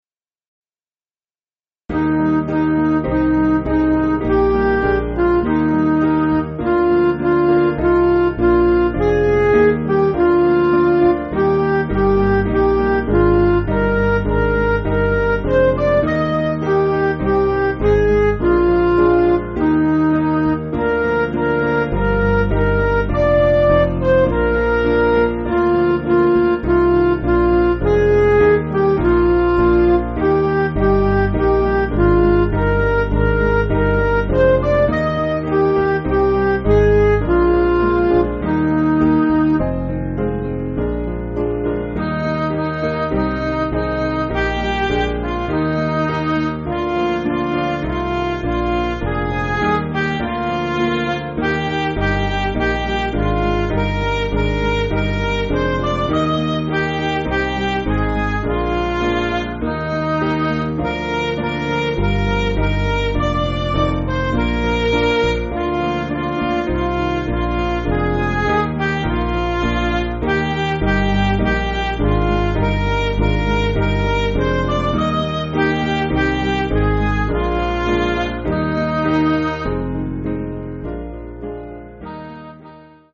Piano & Instrumental
(CM)   4/Eb